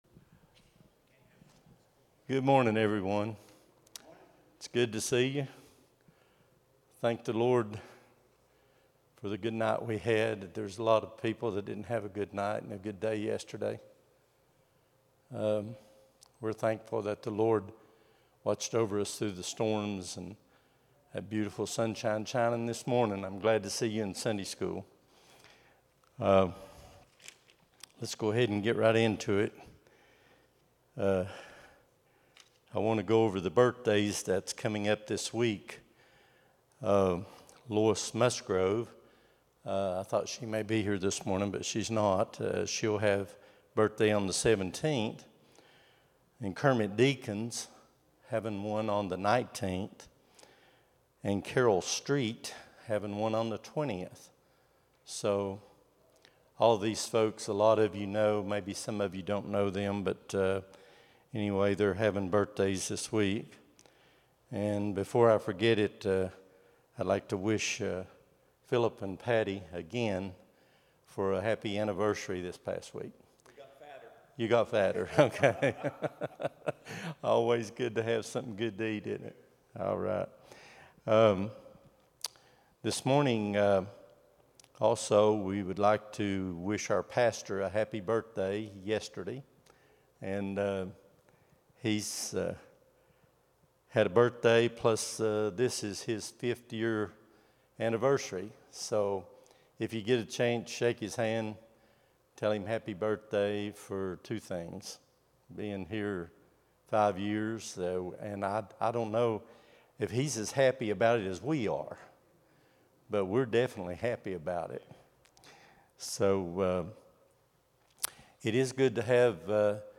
03-16-25 Sunday School | Buffalo Ridge Baptist Church